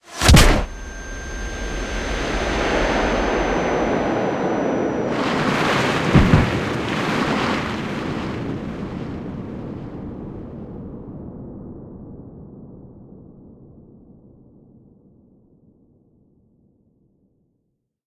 fangPunch.ogg